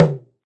africandrum.ogg